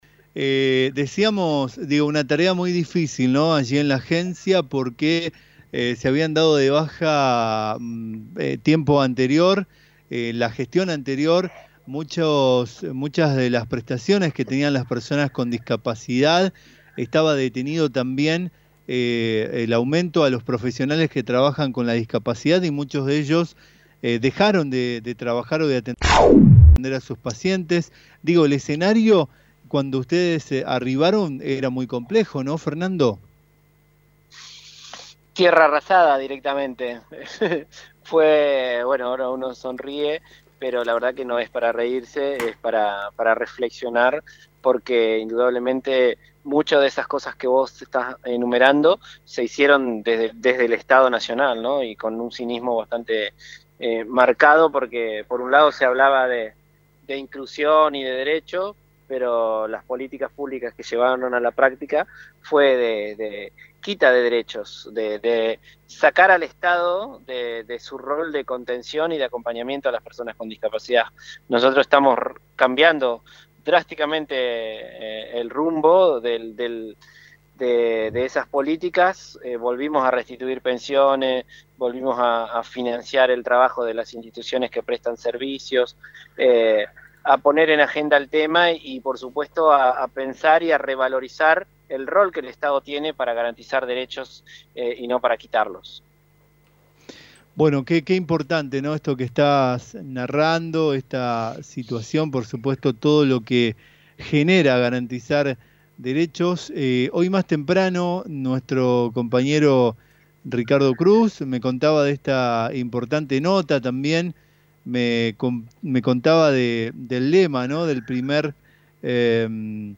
Fernando Galarraga, Licenciado en Comunicación Social y actualmente a cargo de la Agencia Nacional de Discapacidad, en diálogo con LT 39, celebró el acuerdo firmado con la Administración Federal de Ingresos Públicos.